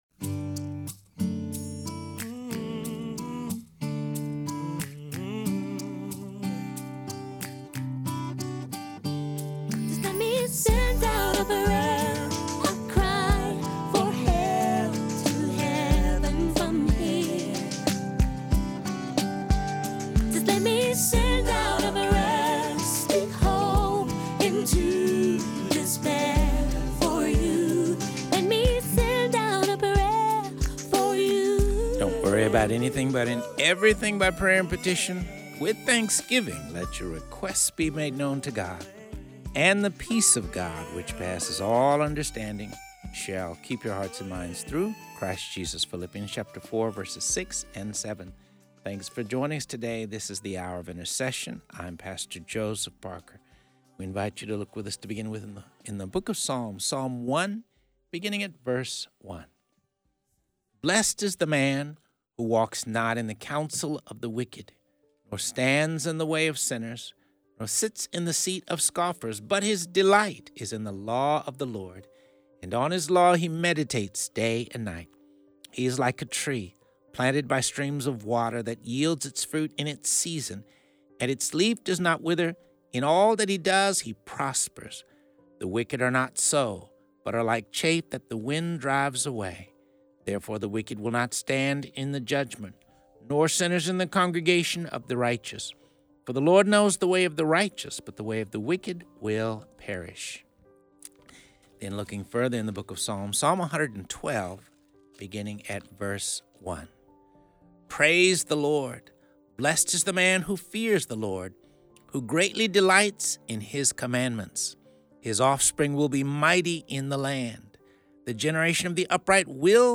This show covers topics of prayer, intercession, the Word of God and features interviews with pastors and religious leaders.